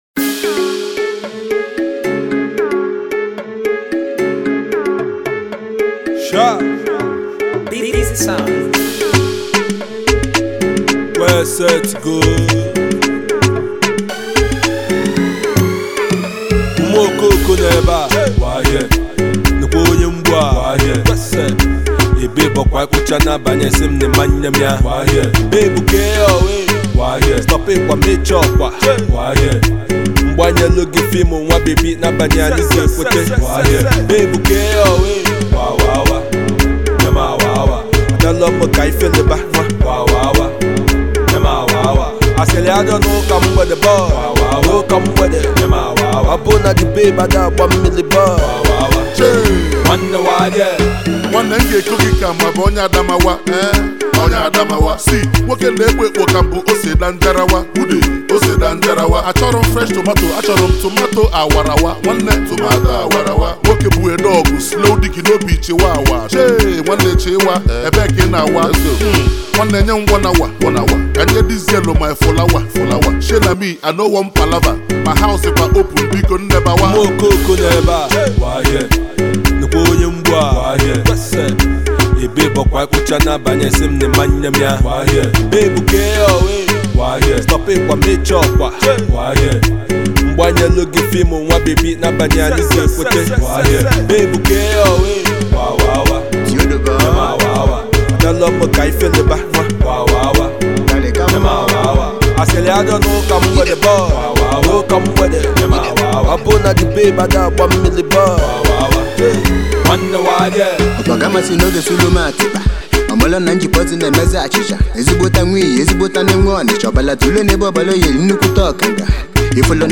this is something for the street and its banging